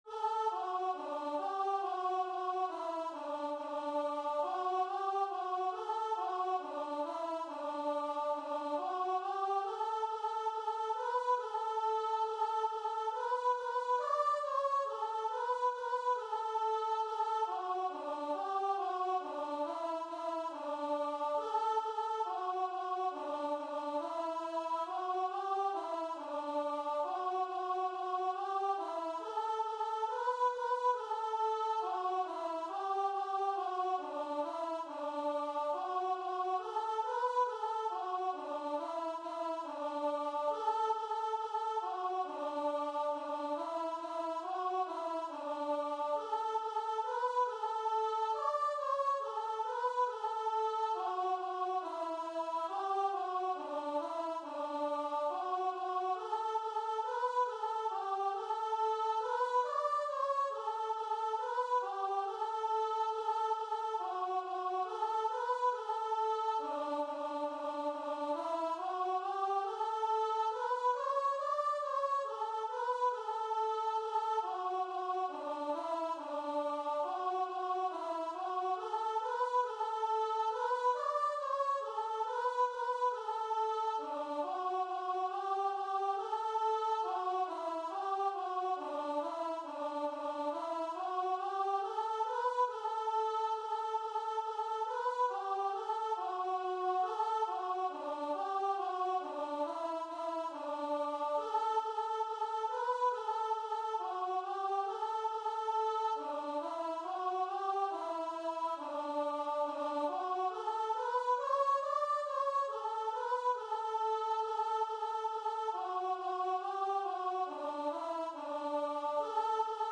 Free Sheet music for Voice
Traditional Music of unknown author.
D major (Sounding Pitch) (View more D major Music for Voice )
4/4 (View more 4/4 Music)
D5-D6
Christian (View more Christian Voice Music)